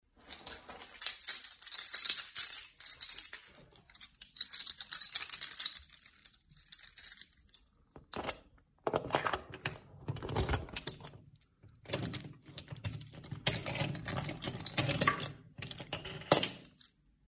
Вынули ключи из кармана и открывают дверь